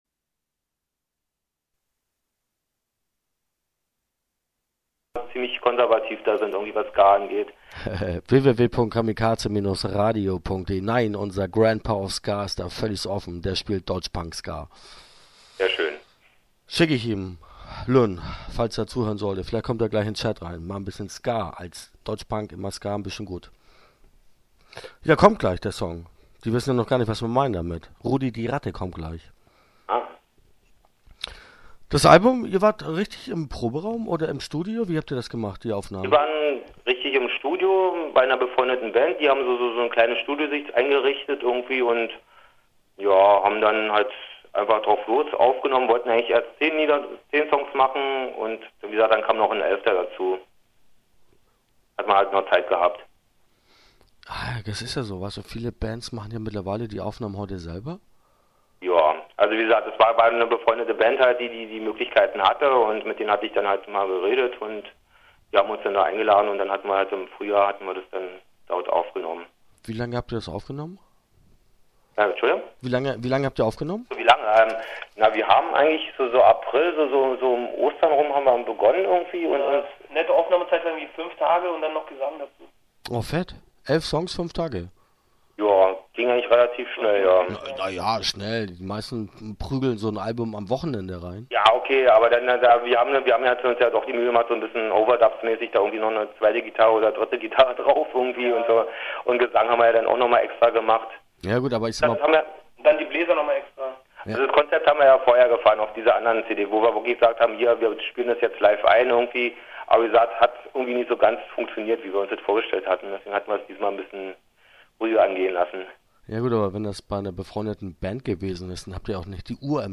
Pommes oder Pizza - Interview Teil 1 (8:20)